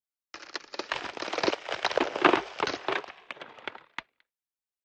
Звуки заморозки
Звук начала обледенения